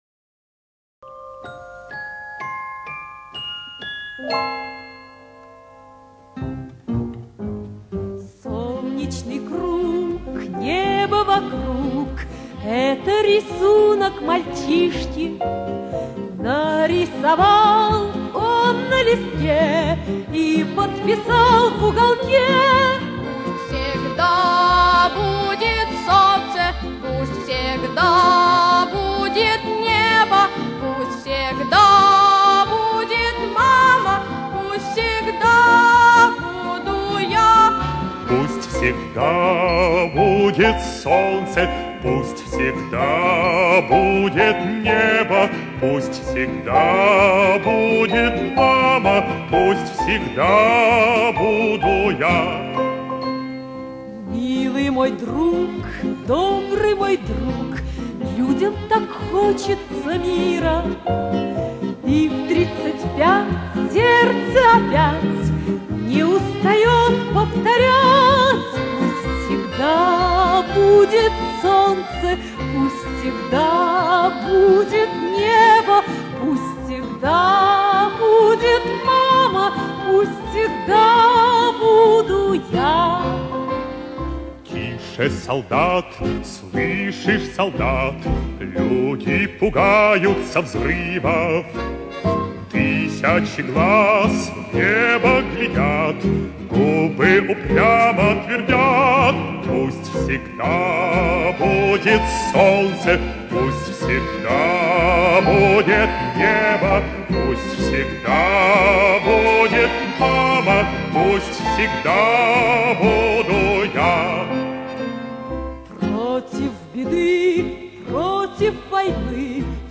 Полная первая запись песни реставрированная
Дуэт